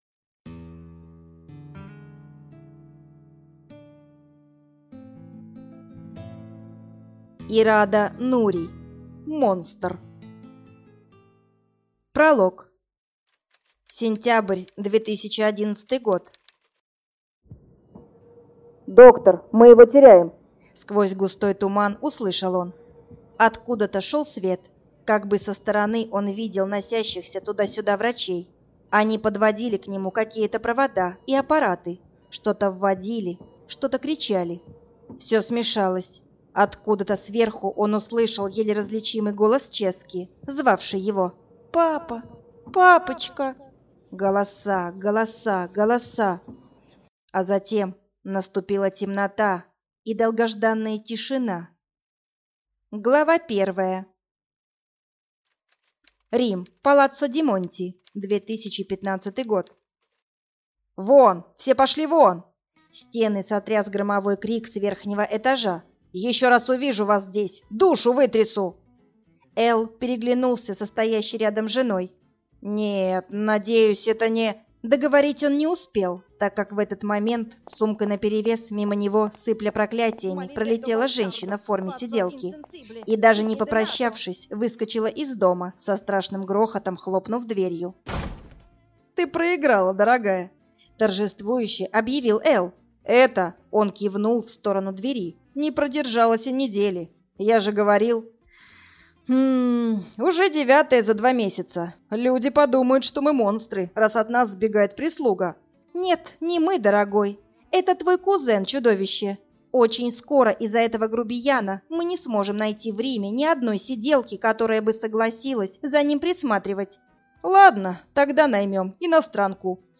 Аудиокнига Монстр | Библиотека аудиокниг